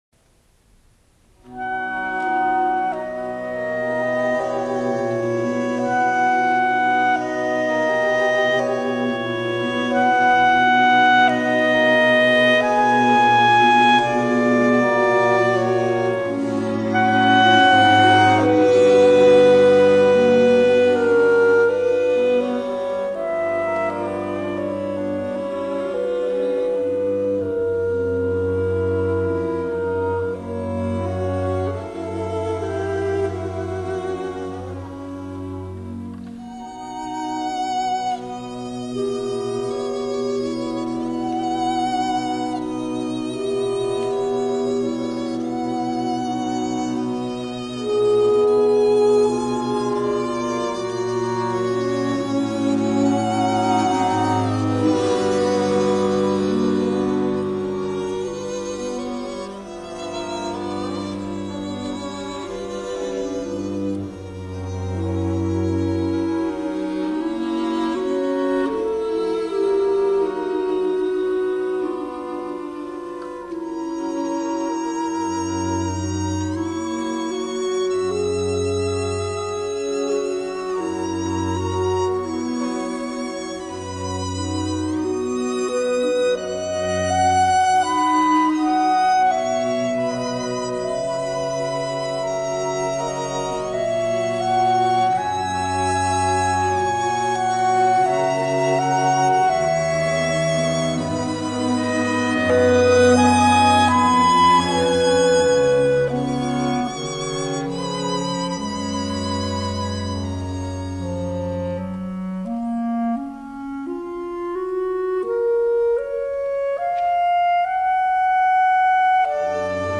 2012 서울 스프링 실내악 축제/강풍/5.2.수 /세종 챔버홀
2012 서울 스프링 실내악 축제 MYSTICAL VOICE 두번째 공연-강풍(Gusty Winds) 5월 2일 (수) 저녁 7시30분 세종문화회관 체임버홀 강풍 (Gusty Winds) 서울스프링실내악축제, 음악을 통한 우정!